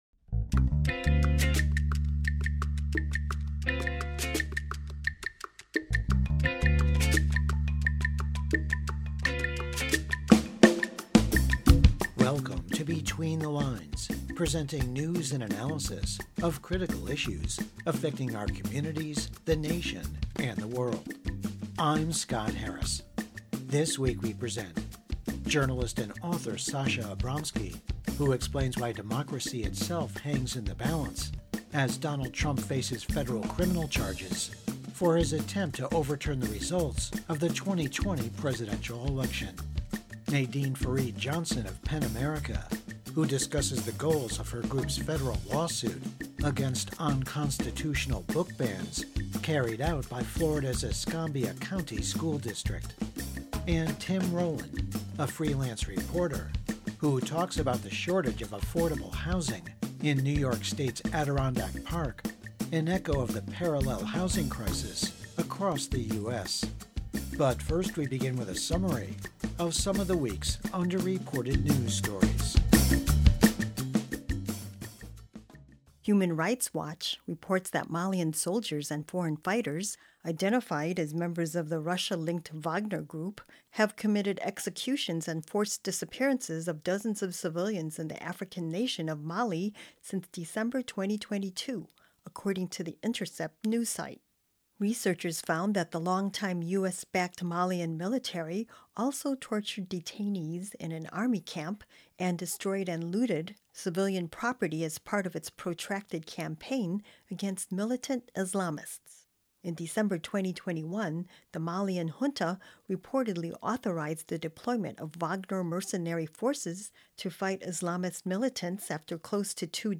Program Type: Weekly Program